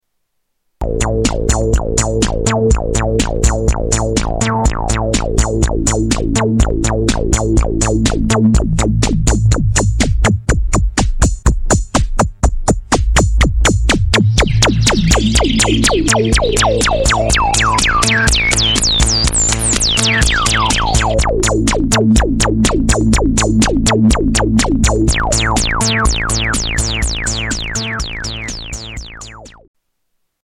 Filter envelope amount
Category: Sound FX   Right: Personal
Tags: Moog Moog Prodigy Moog Prodigy Sounds The Prodigy Synth Sounds